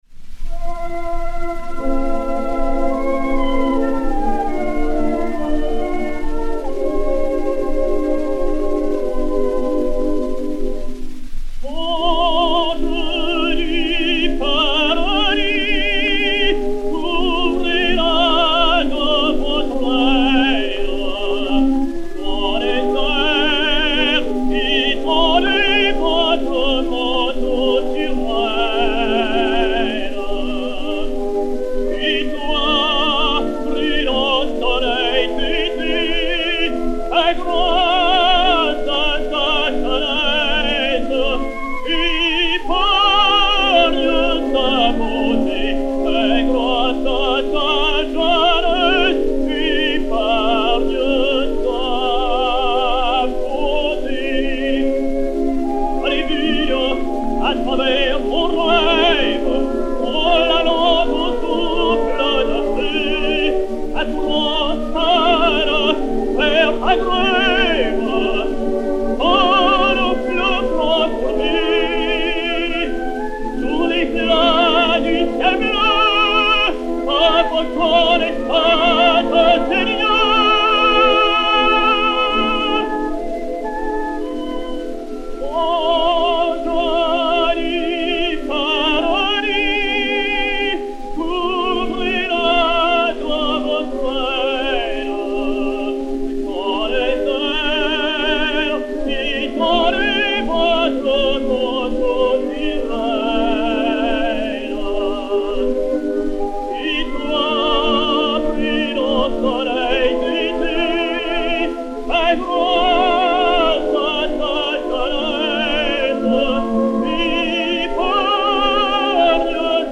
Léon Beyle (Vincent) et Orchestre